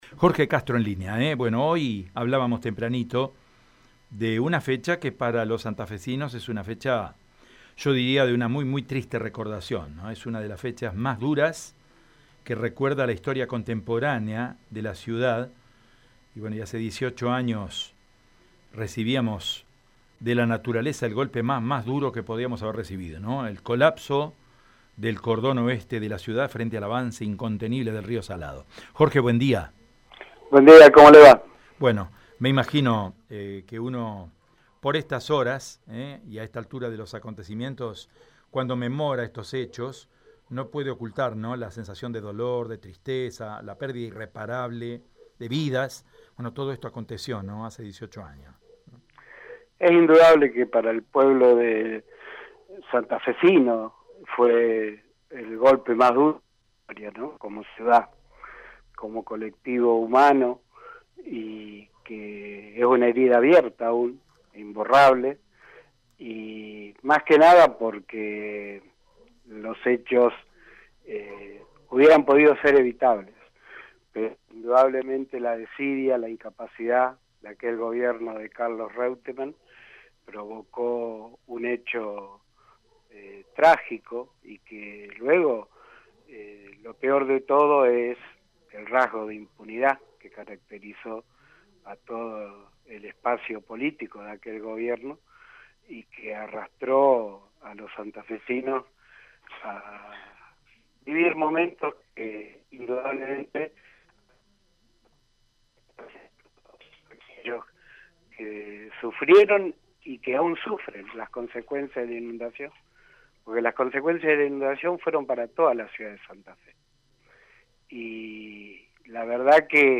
Entrevista en EME